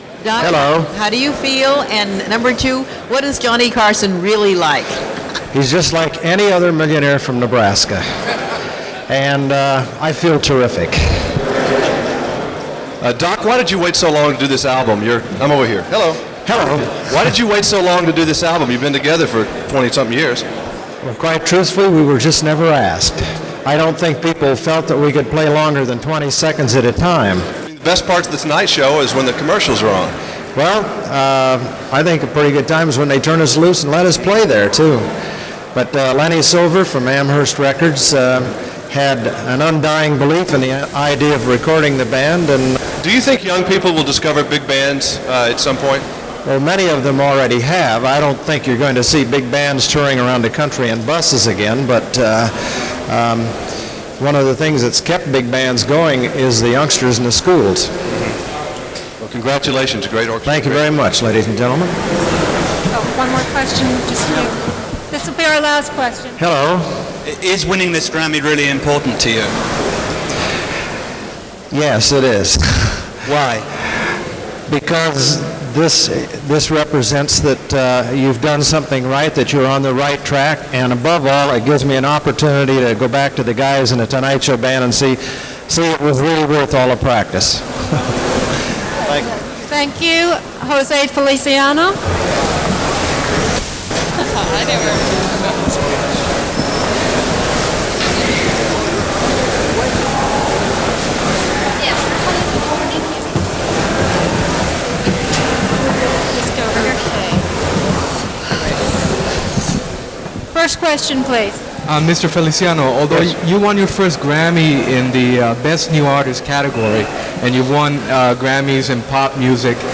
1987 Grammy Awards – Press Conference Interviews – Gordon Skene Sound Collection –
Here’s a little sample of the winners of 1987, as they were rushed to the press area for rapid-fire questions and answers.